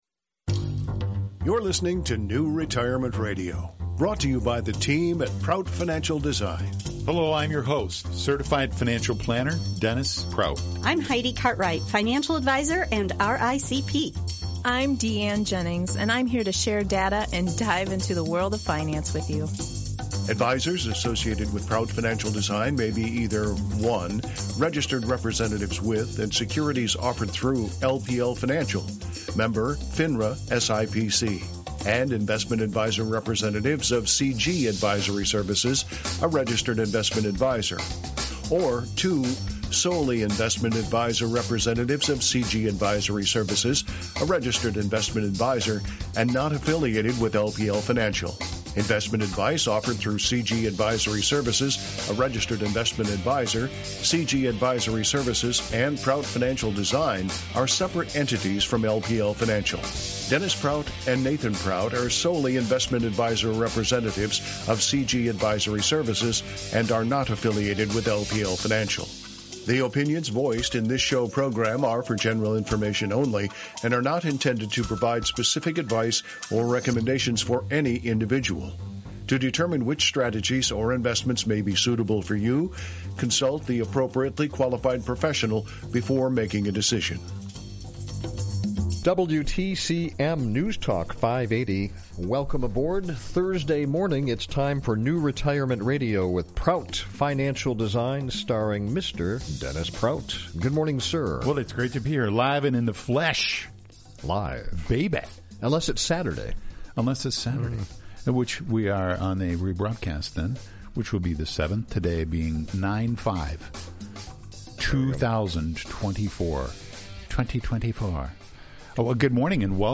We are excited to invite you to hear a phenomenal live presentation